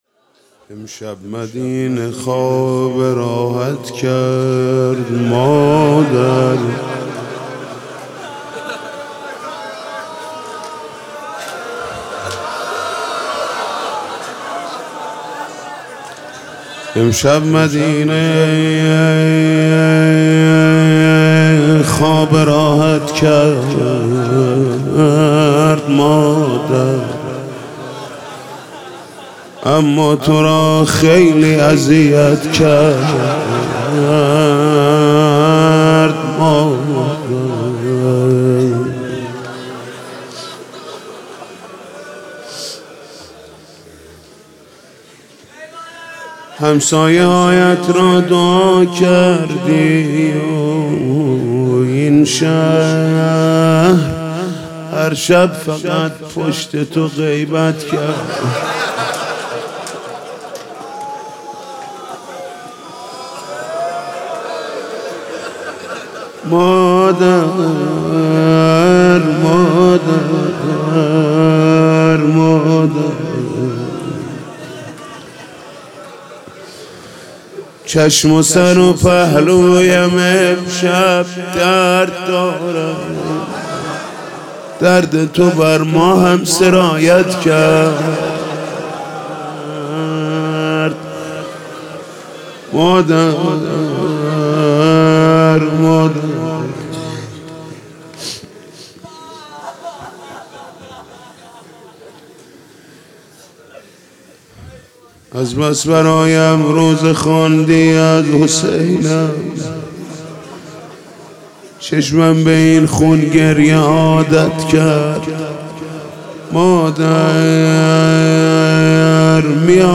فاطمیه 96 شب پنجم روضه محمود کریمی